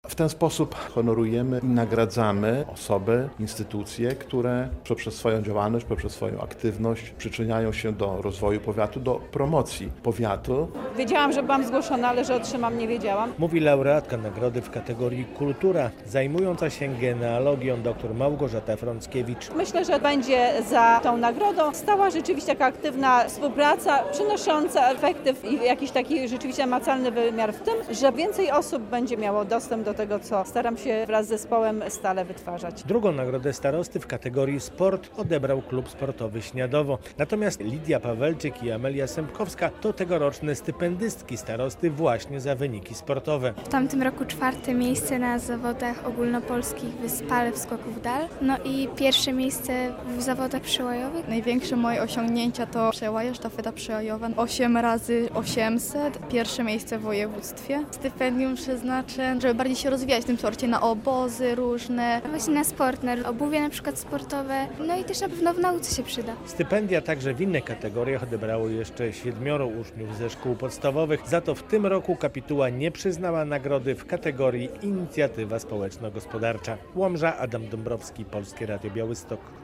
Gala stypendiów i nagród starosty łomżyńskiego, 13.11.2024, fot.